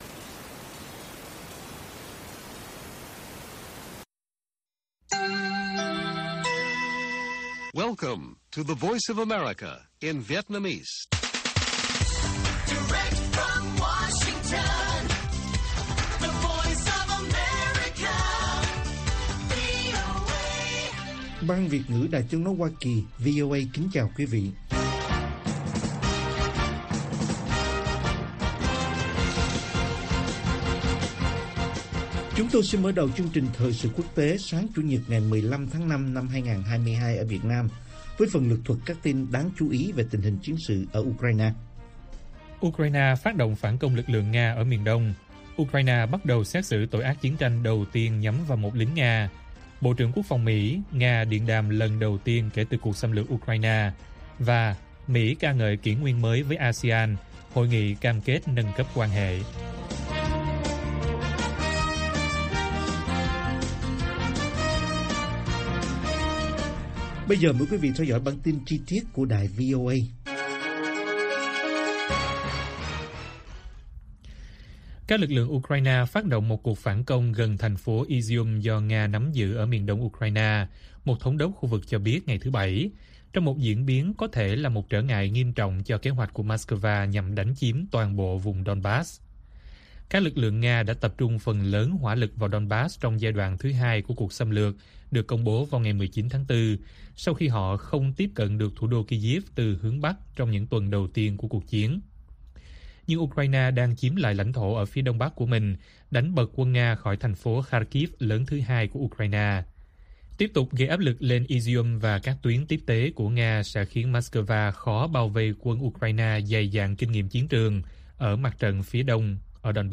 Ukraine phát động phản công lực lượng Nga ở miền đông - Bản tin VOA